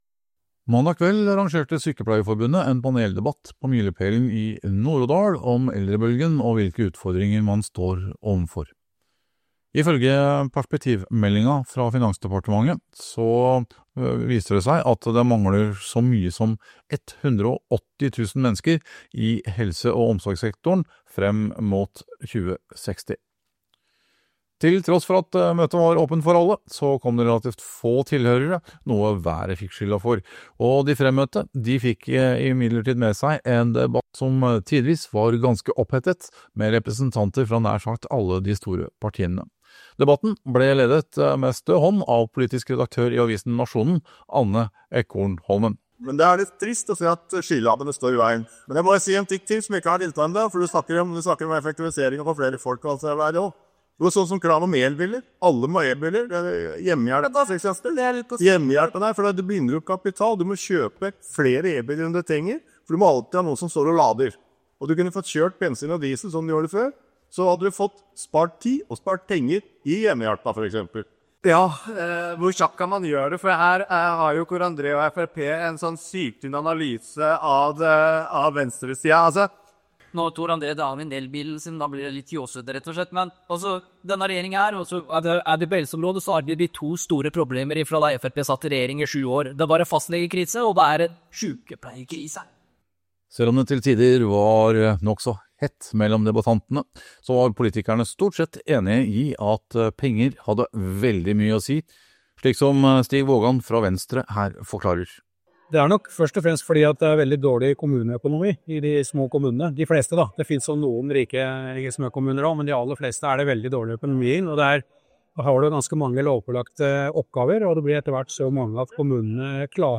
Mandag kveld arrangerte Sykepleierforbundet en paneldebatt på Milepælen i Nord-Odal om utfordringene knyttet til eldrebølgen og framtidas behov i helse- og omsorgssektoren. Ifølge perspektivmeldingen fra Finansdepartementet vil det mangle opptil 180 000 ansatte i sektoren innen 2060.